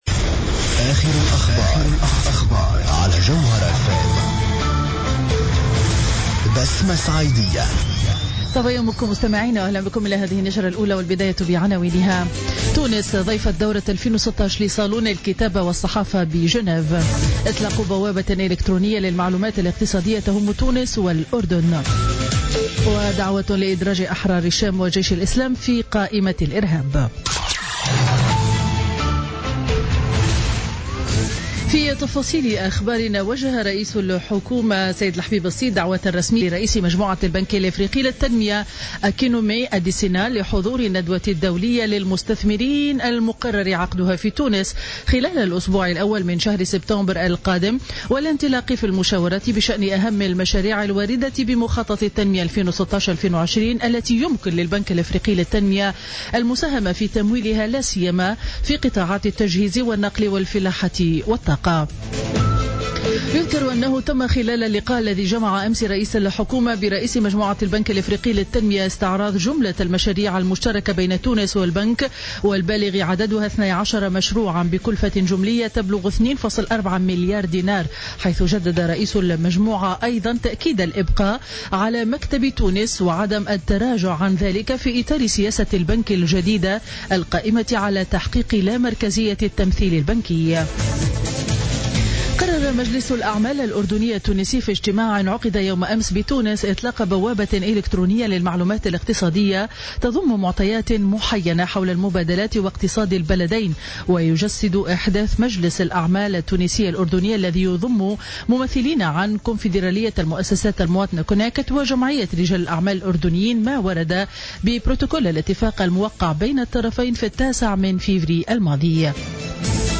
نشرة أخبار السابعة صباحا ليوم الأربعاء 27 أفريل 2016